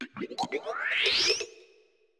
Cri de Floréclat dans Pokémon Écarlate et Violet.